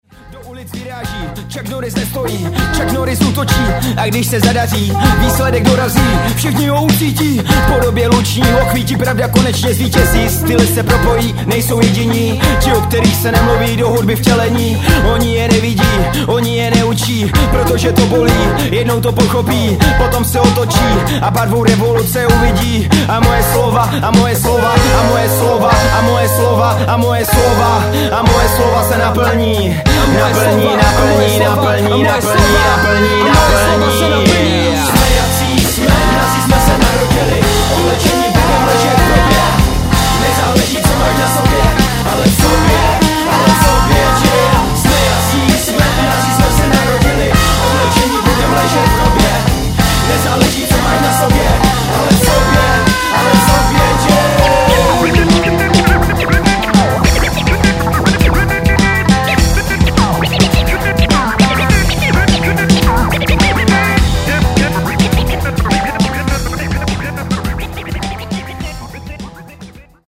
rap blues